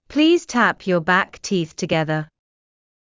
ﾌﾟﾘｰｽﾞ ﾀｯﾌﾟ ﾕｱ ﾊﾞｯｸ ﾃｨｰｽ ﾄｩｹﾞｻﾞｰ